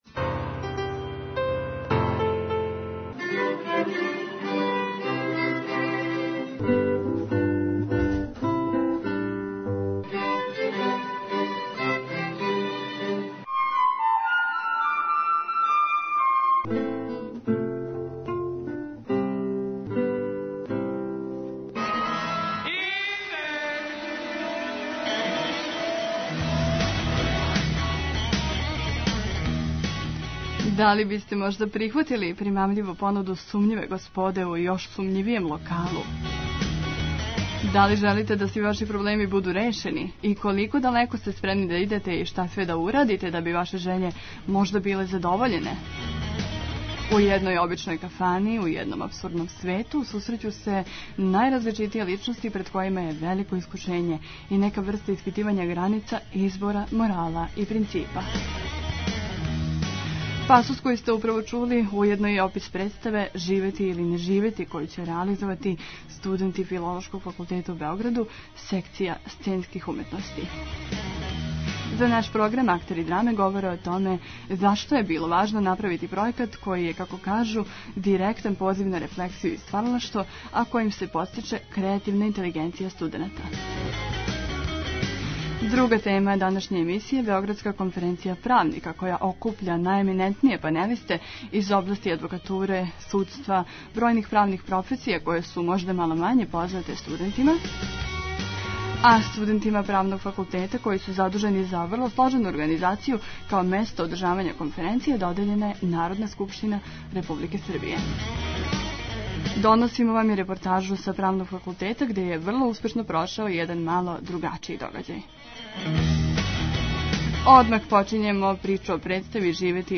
За наш програм актери драме говоре о томе зашто је било важно направити пројекат који је, како кажу, директан позив на рефлексију и стваралаштво којим се подстиче креативна интелигенција студената.
A доносимо и репортажу са Правног факултета где је врло успешно прошао један мало другачији догађај.